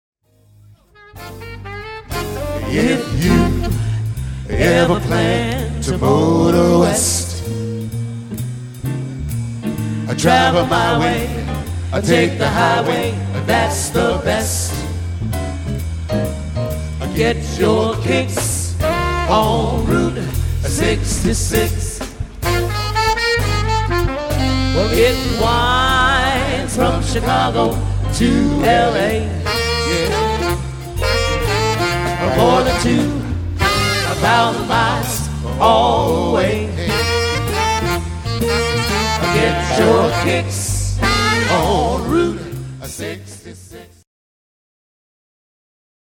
Jazz, Standards